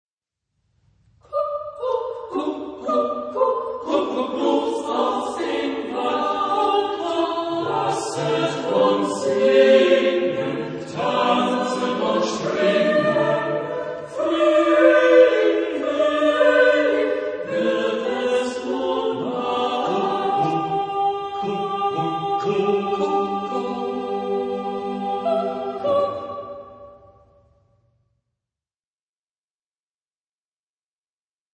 Genre-Stil-Form: Volkslied ; Liedsatz ; weltlich
Chorgattung: SATB  (4 gemischter Chor Stimmen )
Tonart(en): G-Dur